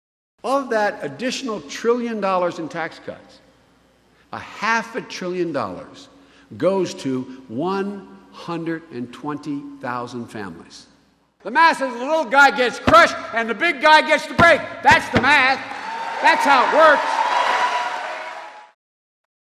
Biden said if Romney and his fellow Republicans enacted a continued income tax cut for the rich, it would force either massive government spending cuts, or a tax hike on the middle class. The vice president spoke at Marion Harding High School whose sports team are called --- the presidents.